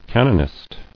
[can·on·ist]